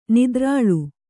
♪ nidrāḷu